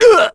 Mitra-Vox_Damage_02.wav